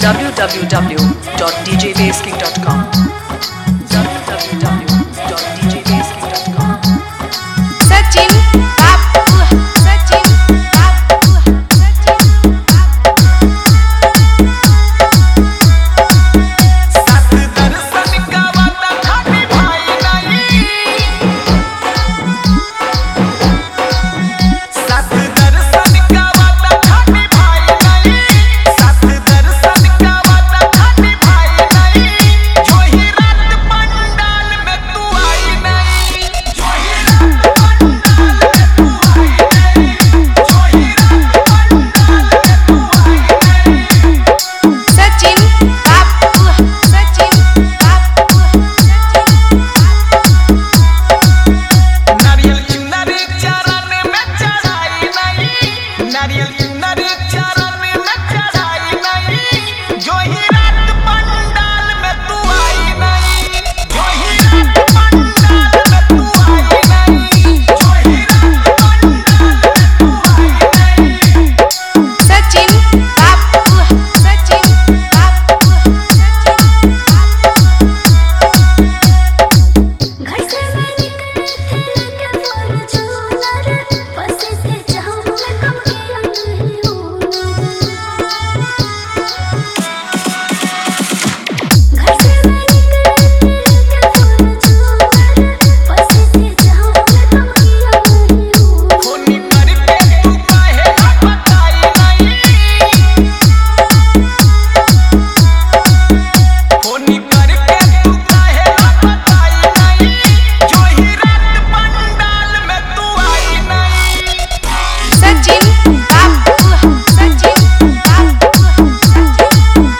Navratri Dj Remix Songs